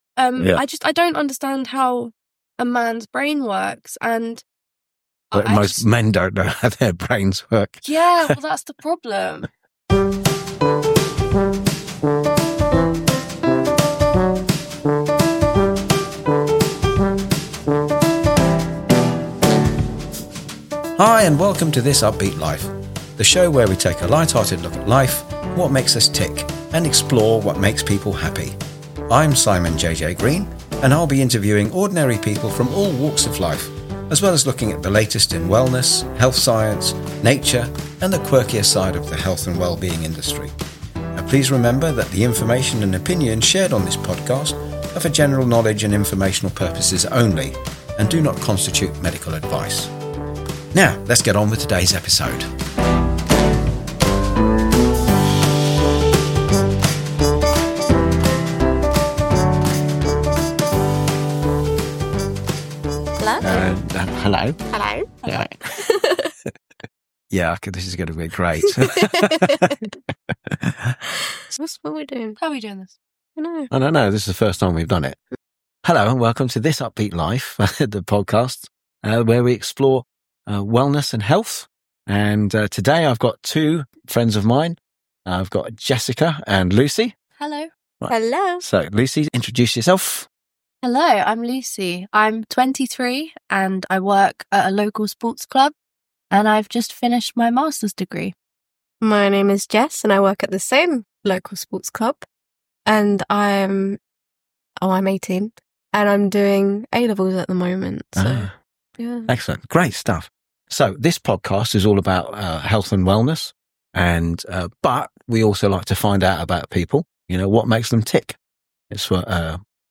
This was my first time ever interviewing anyone.